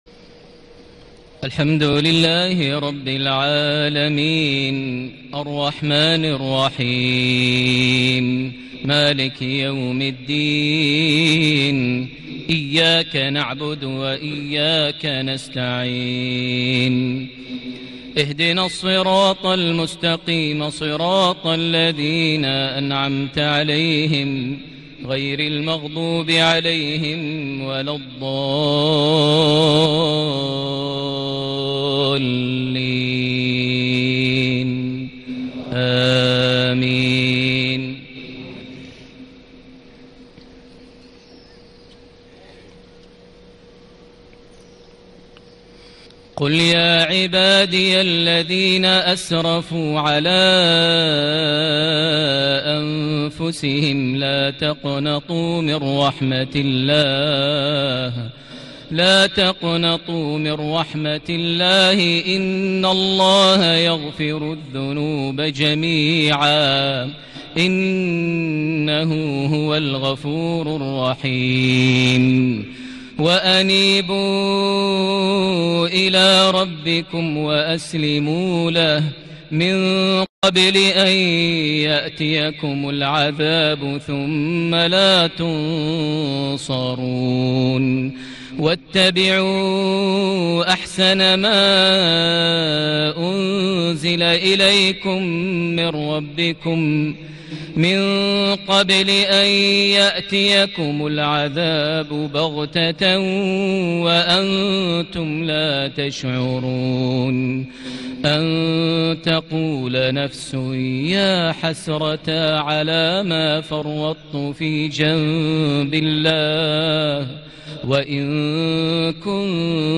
صلاة المغرب ١٢ محرم ١٤٤٠هـ سورة الزمر ( ٥٣-٦٦) > 1440 هـ > الفروض - تلاوات ماهر المعيقلي